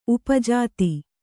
♪ upa jāti